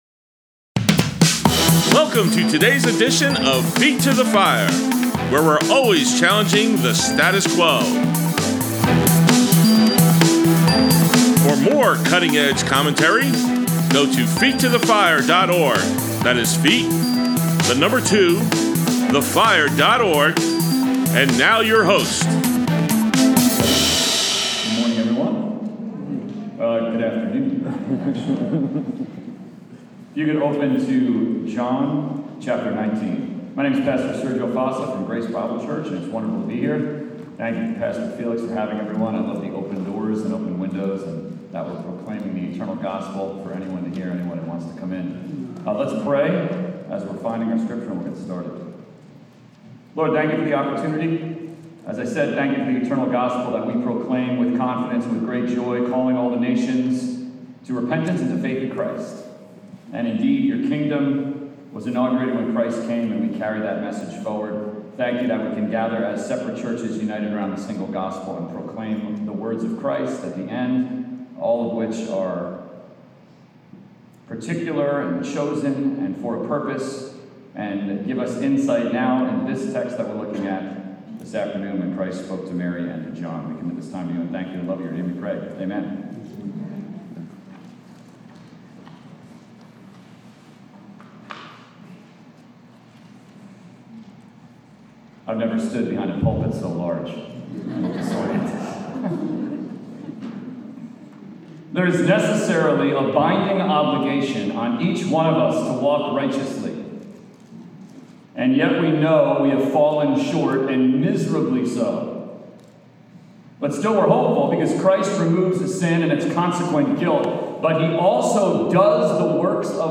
Saturday Sermons: The Last Words of Christ, “Woman, Behold Your Son!”